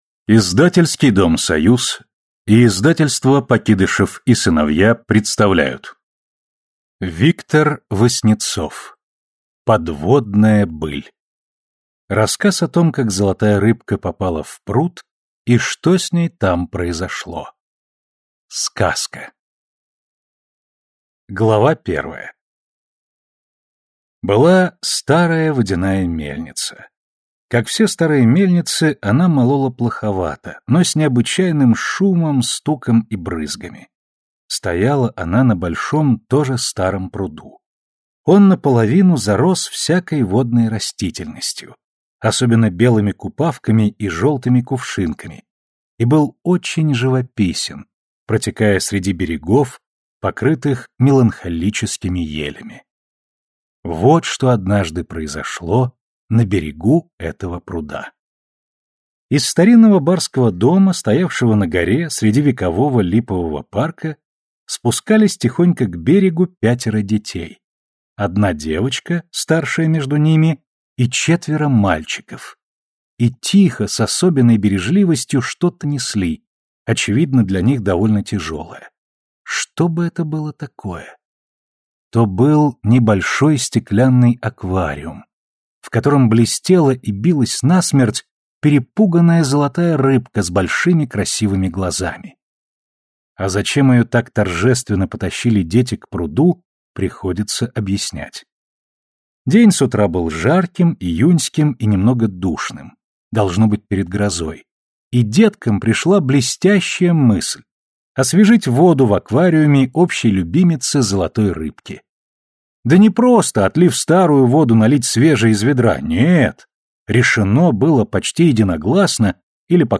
Аудиокнига Подводная быль | Библиотека аудиокниг